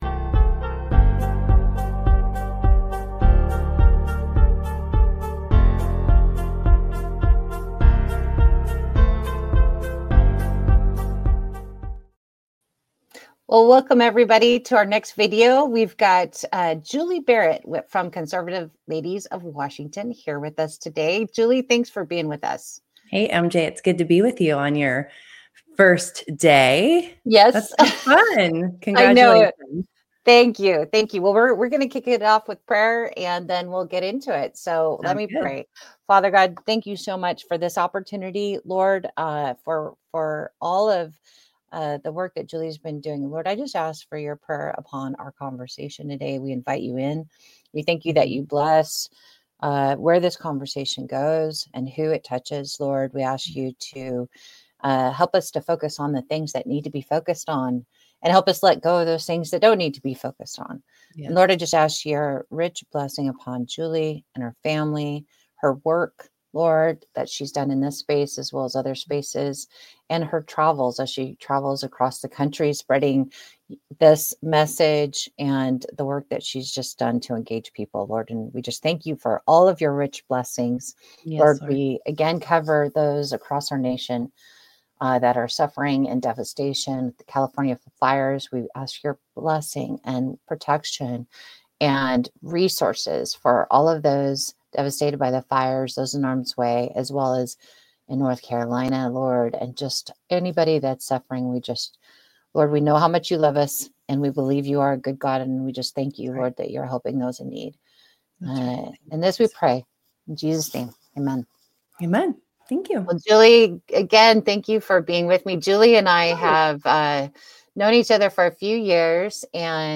UnBOLTED: Special Interview